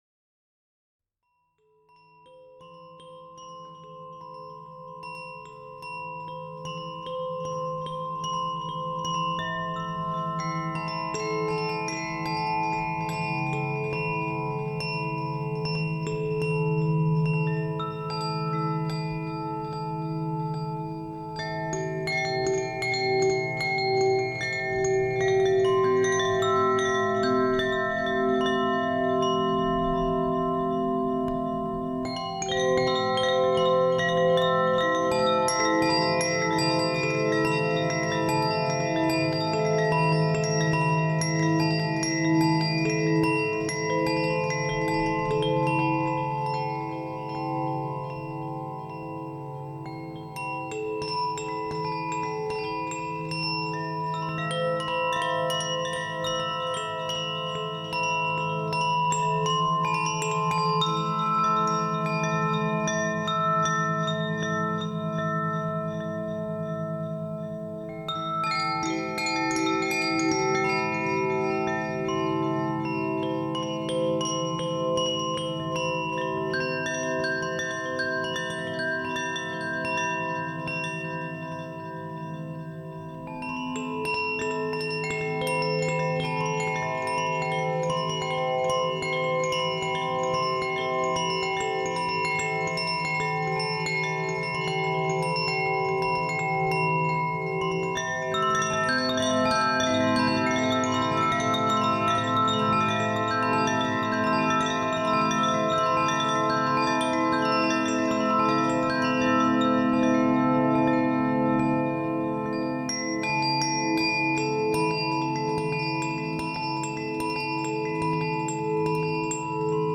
Musique koshi air et terre